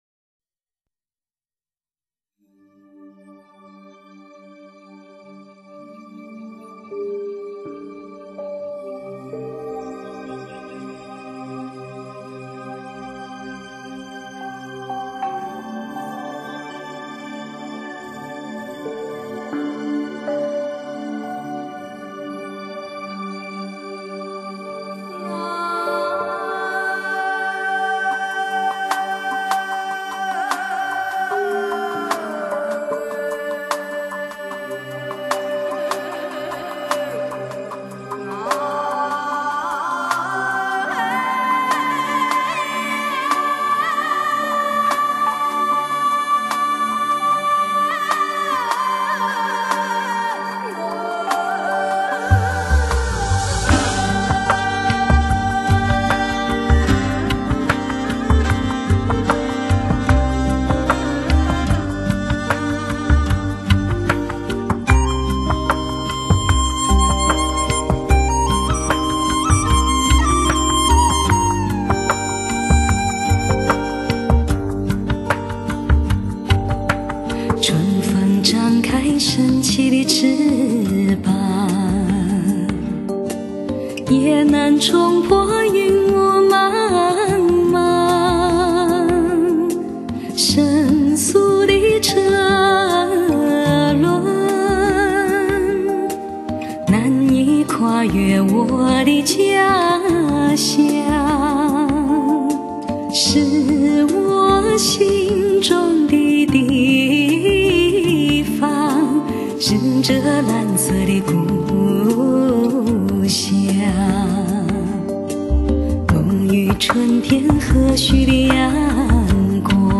真实的唱 忧伤的唱 镇定的唱 唱给每个拥有回忆的人